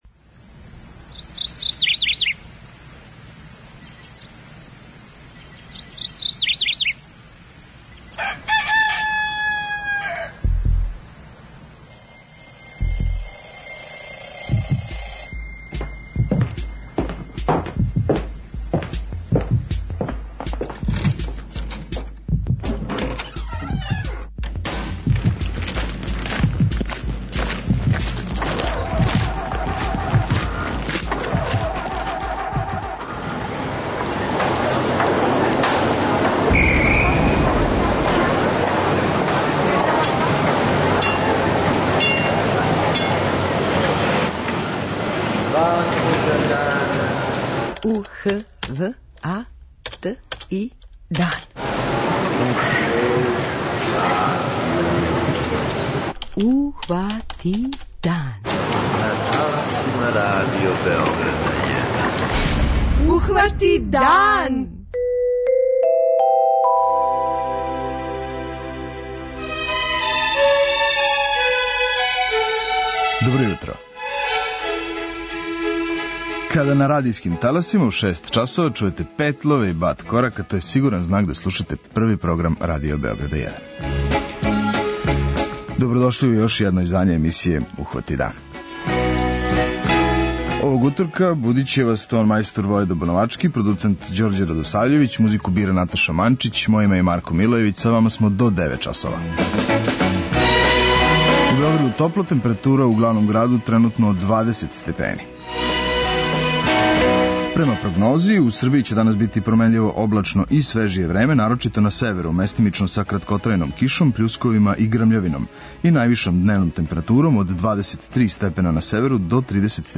Наш гост овога јутра је амбасадор Специјалне Олимпијаде и легенда наше одбојке, Владимир Грбић.